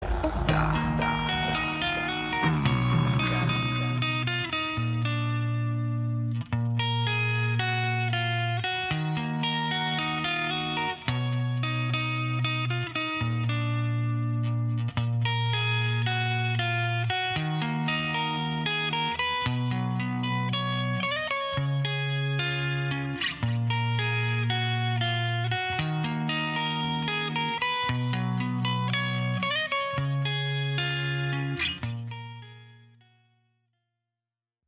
• Качество: 192, Stereo
спокойные
красивая мелодия
акустика
Инструментал под гитару